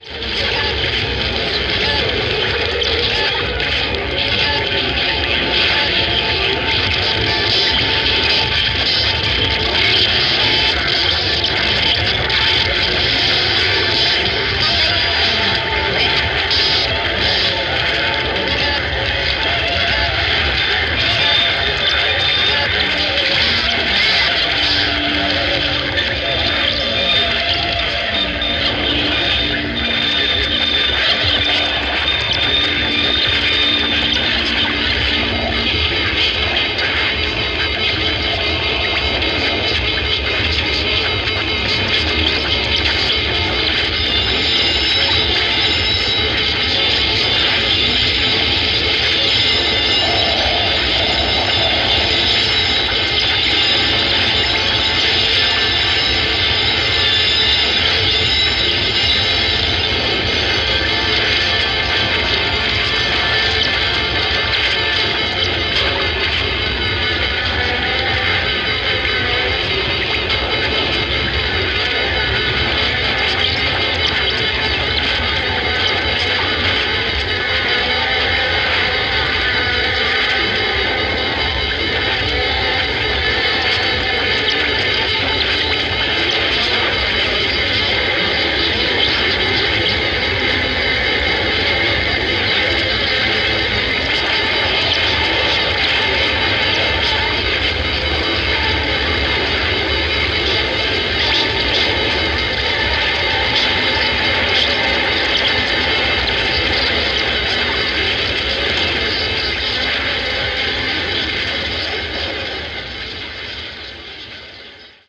modified transistor radio
modified turntable
modified magnetic tape